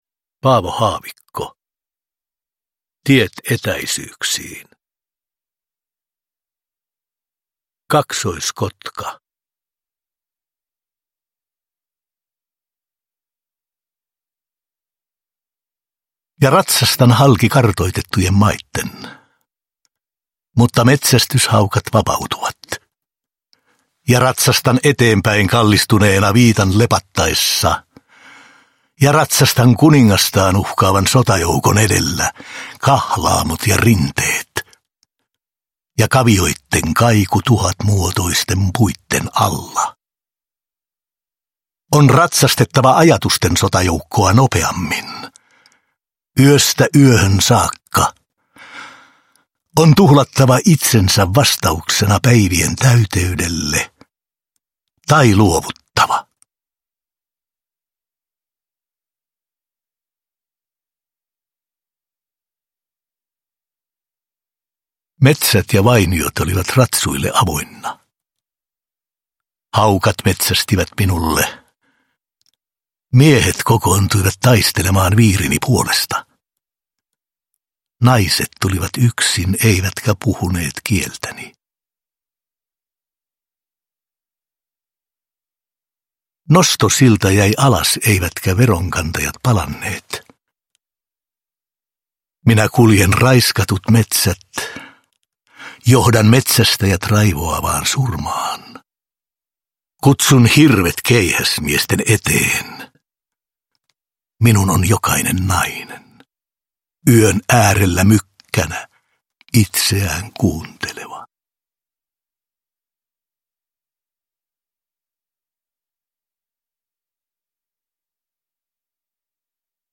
Uppläsare: Kalle Holmberg